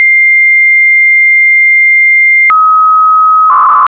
Encode text as FSK modem audio.